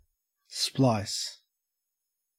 Ääntäminen
IPA : /splaɪs/